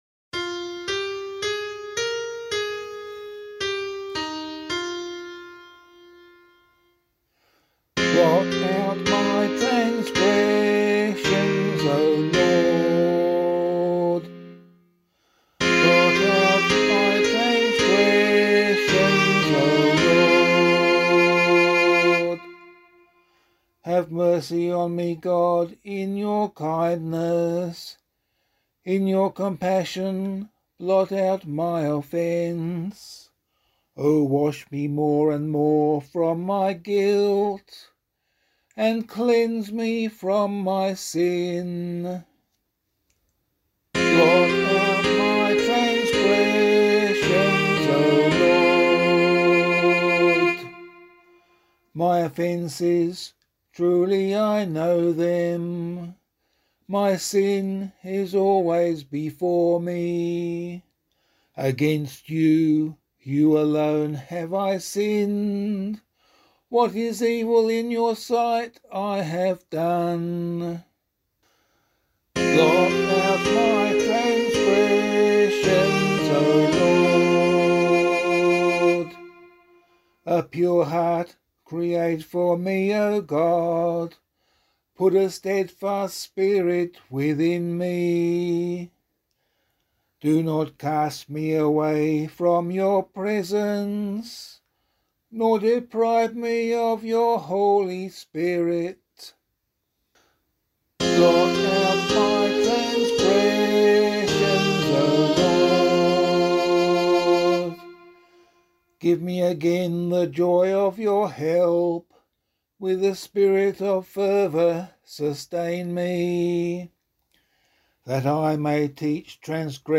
012 Ash Wednesday Ashes [LiturgyShare 2 - Oz] - vocal.mp3